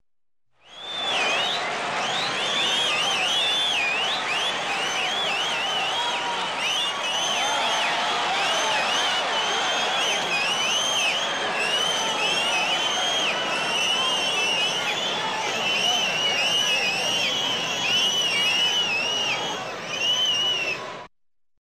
Звук: оглушительный свист толпы на ночном гулянии